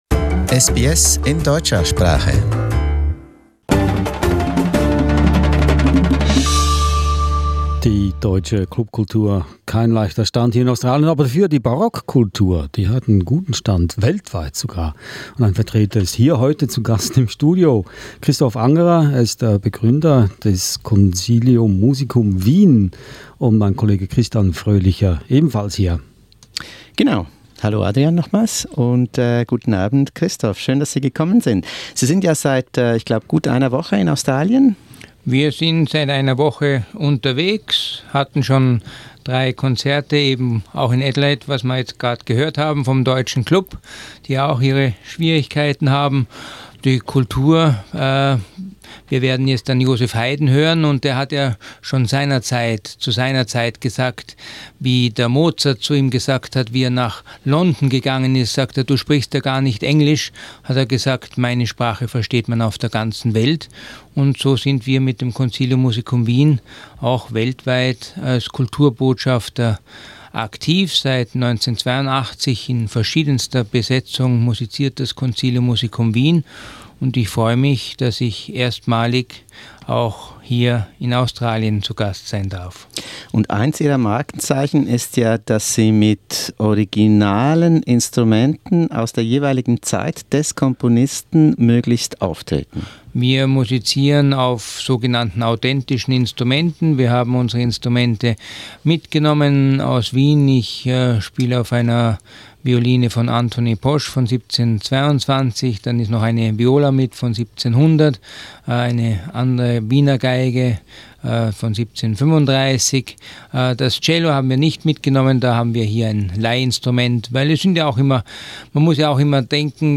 bei seinem Besuch im SBS Funkhaus in Melbourne